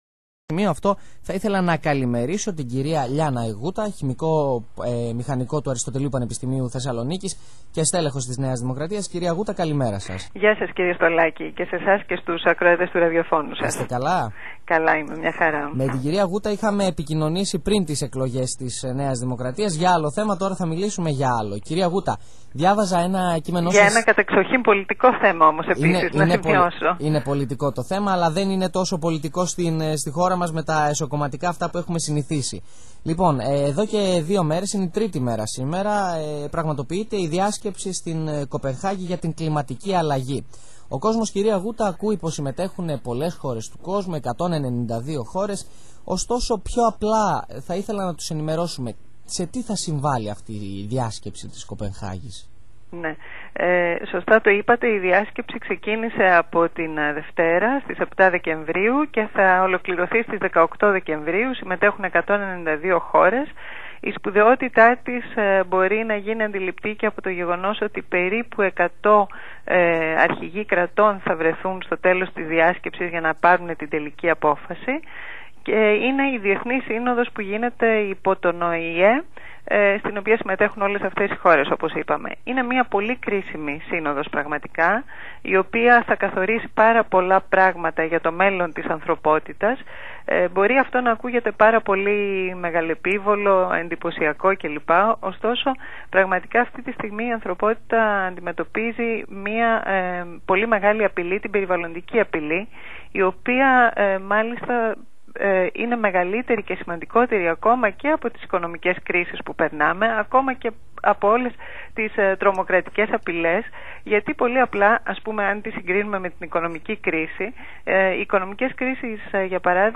Συνέντευξη στο ΡΑΔΙΟ ΓΝΩΜΗ, 93.7 FM